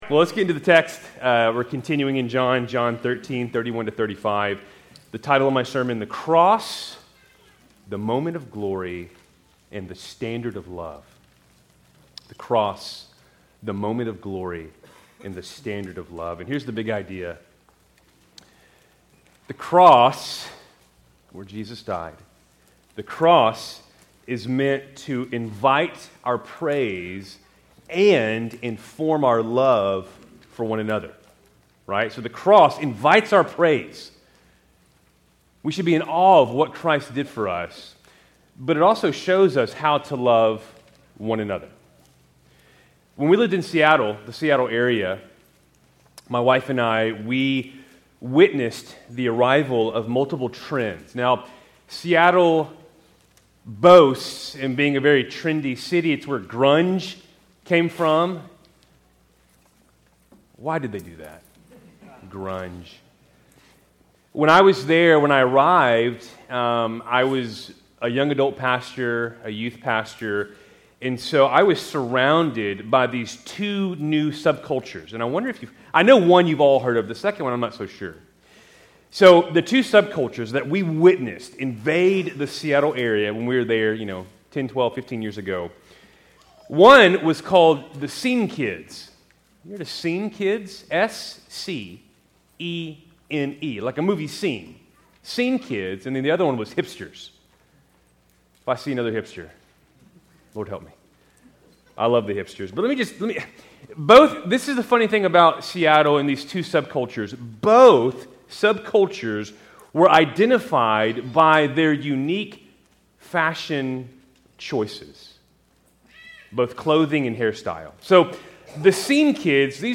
Keltys Worship Service, September 14, 2025